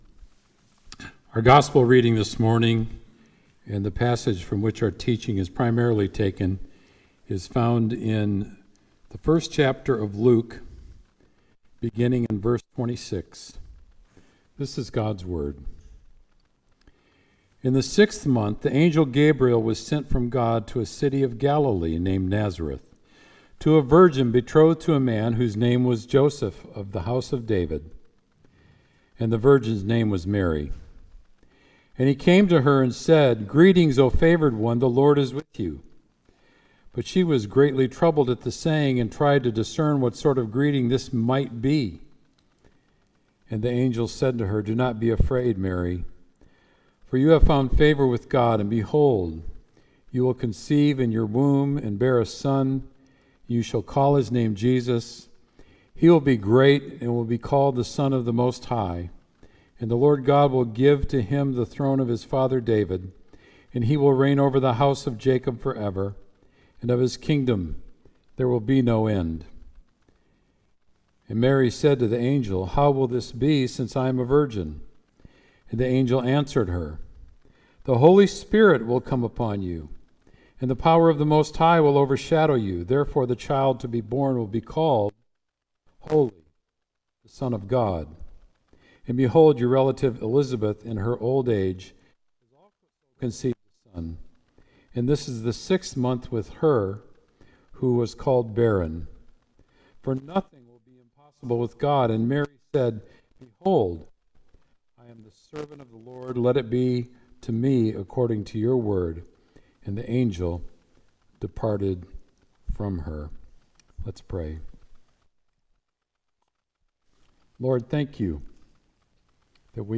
Sermon “How Can This Be?”